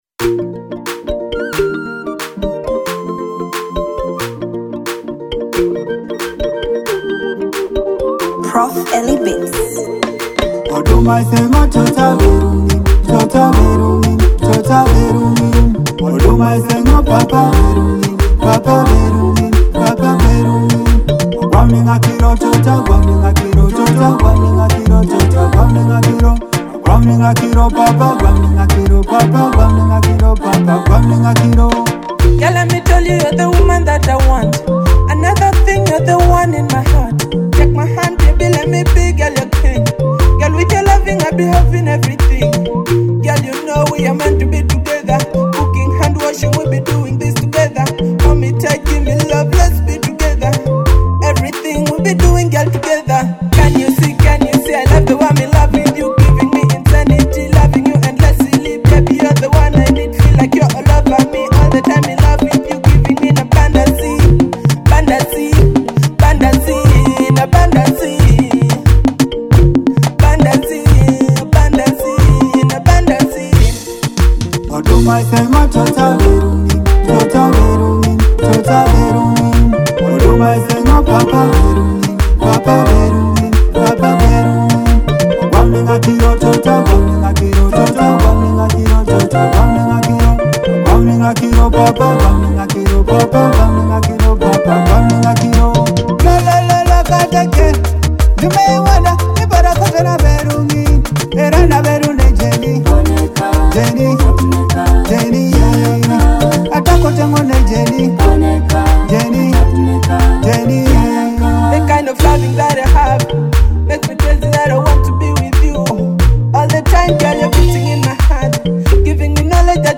an electrifying Kadongo Kamu hit.